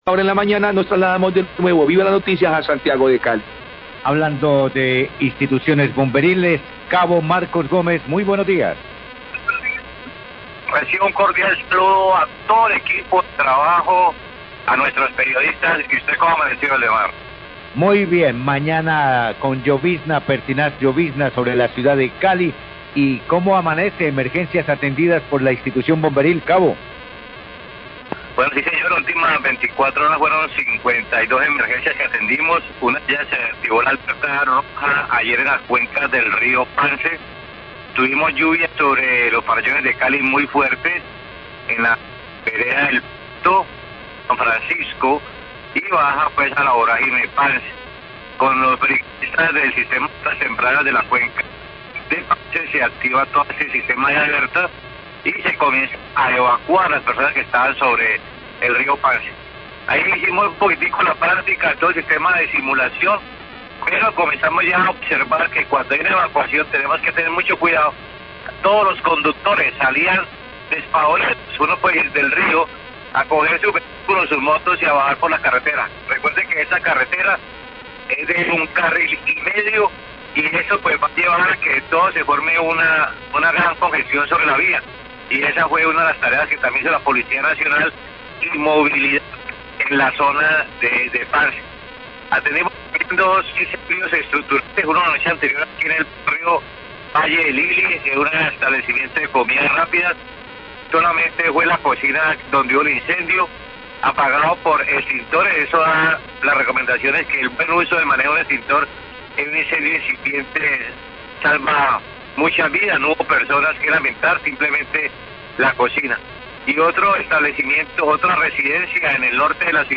Radio
reporte de bomberos